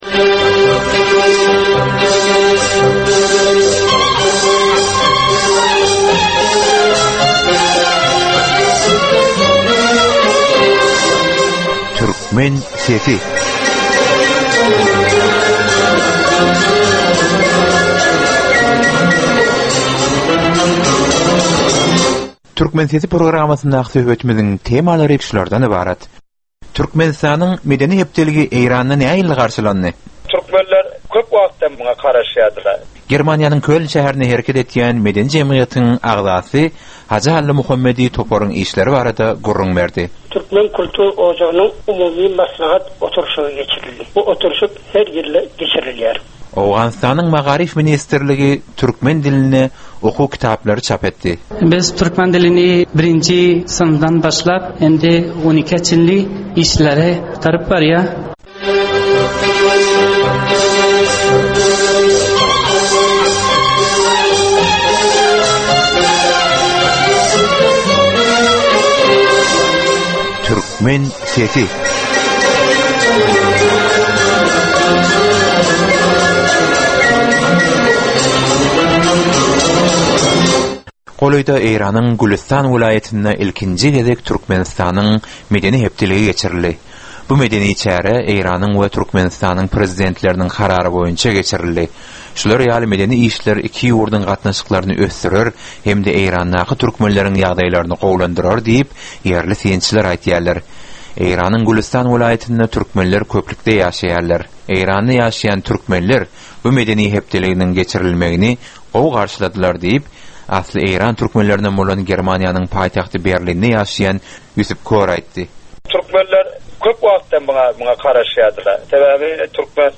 Dünýä türkmenleriniň durmuşyna degişli wakalara, meselelere we täzeliklere bagyşlanylyp taýýarlanylýan ýörite gepleşik. Bu gepleşikde dünýäniň dürli ýurtlarynda we Türkmenistanda ýaşaýan türkmenleriň durmuşyna degişli maglumatlar, synlar, bu meseleler boýunça synçylaryň we bilermenleriň pikirleri we teklipleri berilýär.